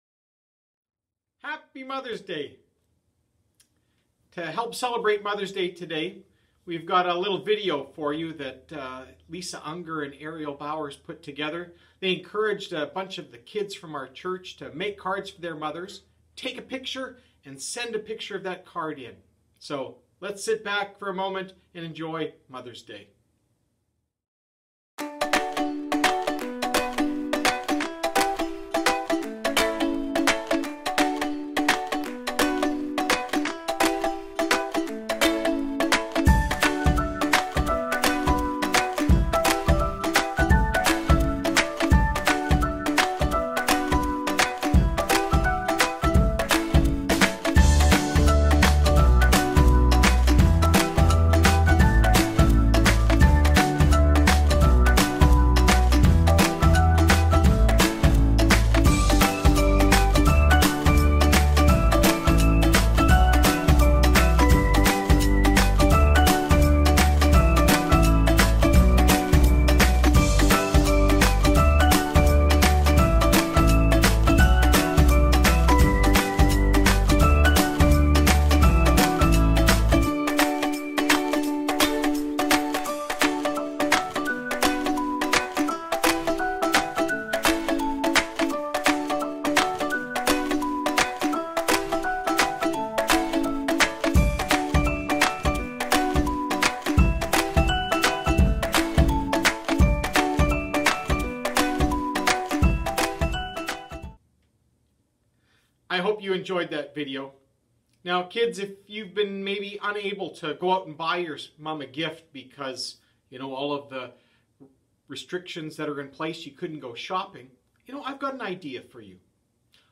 Mother's Day Sermon